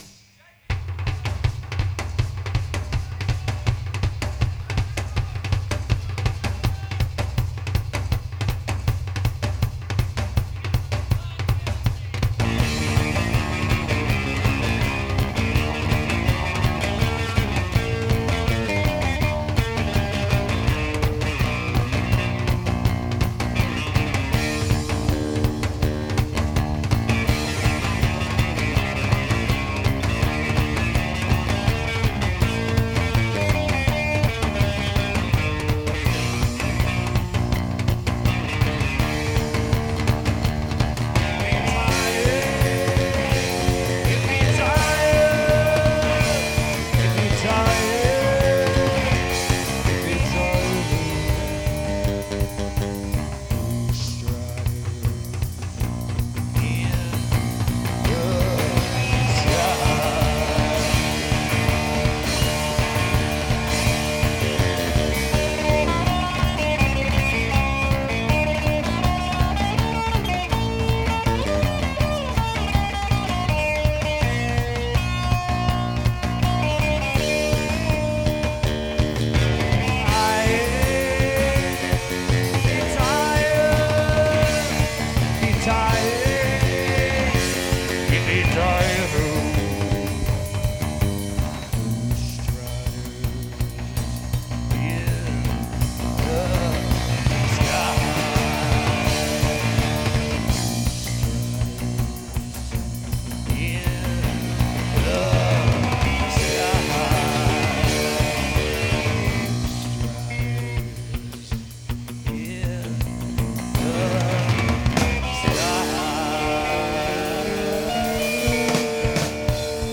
This review is on the soundboard show.